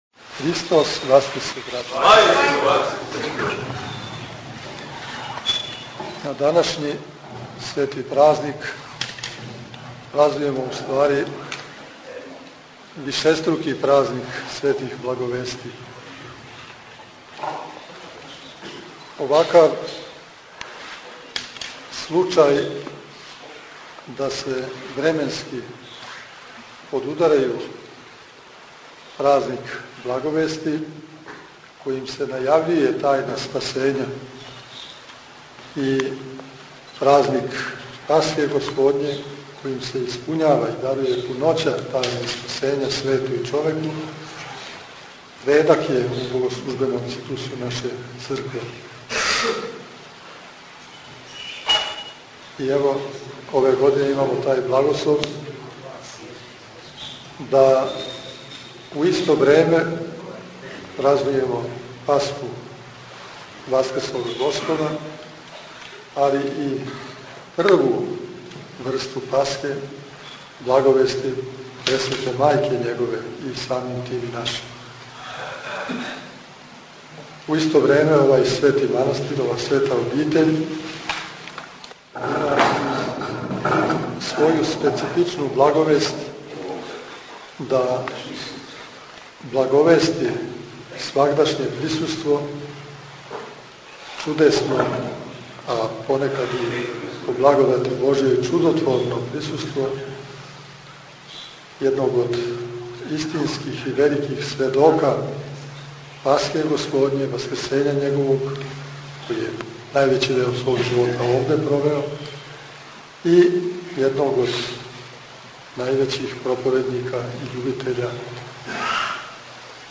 У манастиру Ћелије код Ваљева, 7. априла 2010. године, свечано је обележен празник Благовести.
Звучни запис беседе Епископа Иринеја
Ep_Irinej_Celije_2010.mp3